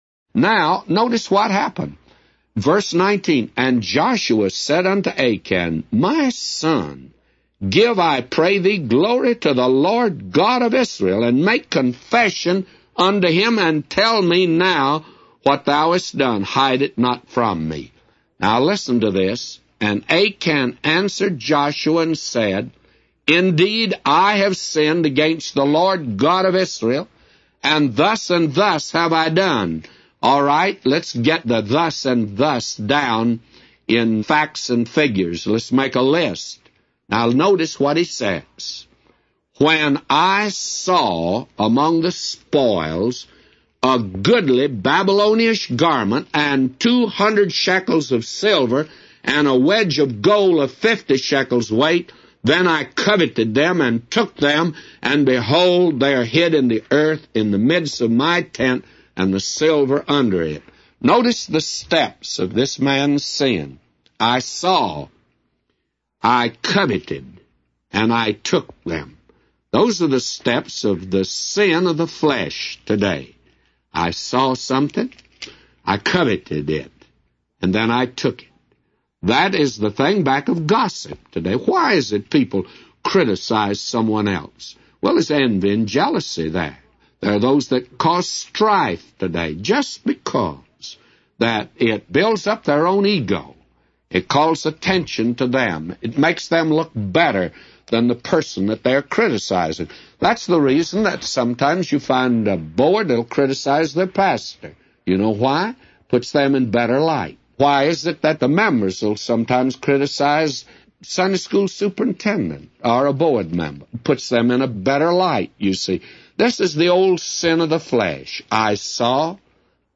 A Commentary By J Vernon MCgee For Joshua 7:10-999